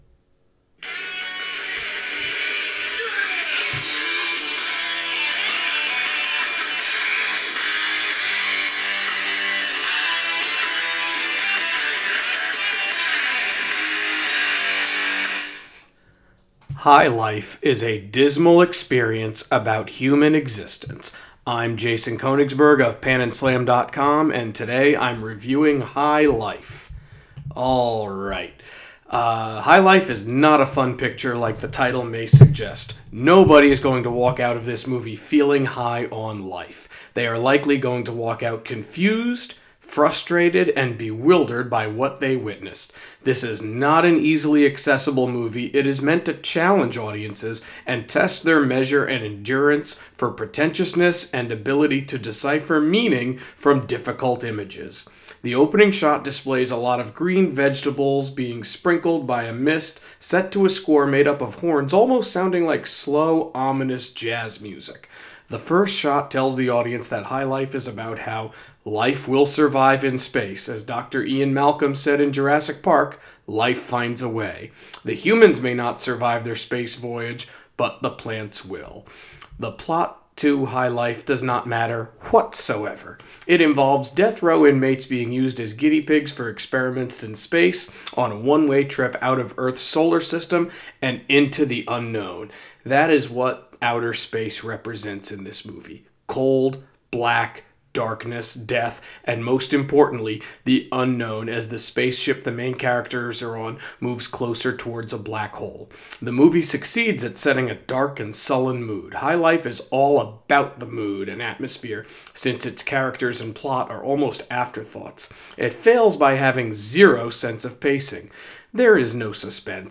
Movie Review: High Life